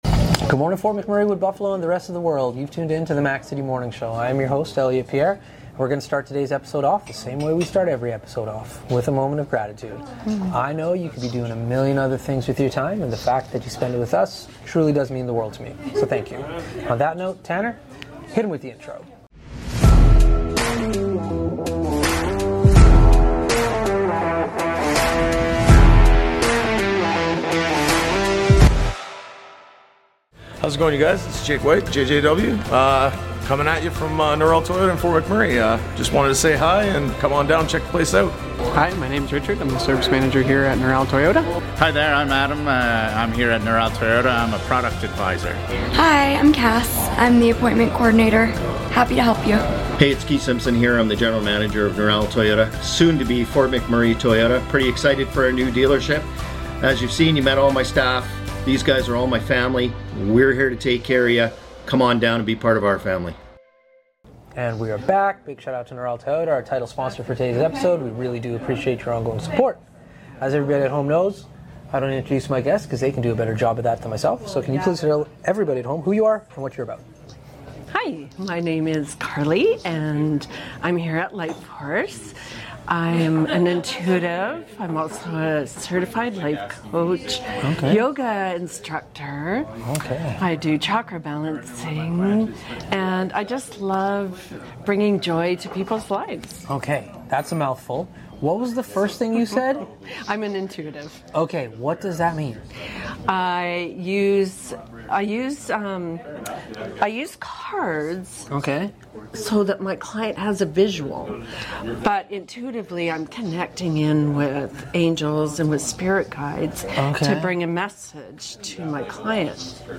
Back on location today